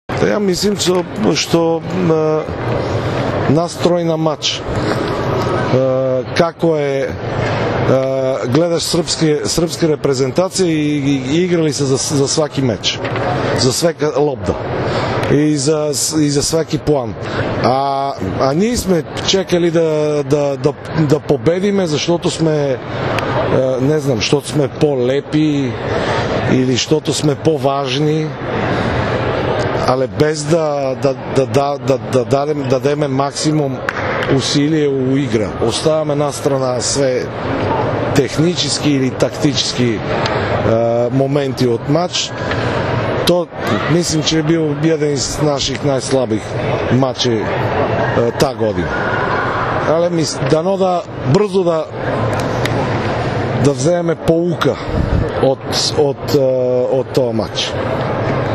IZJAVA PLAMENA KONSTANTINOVA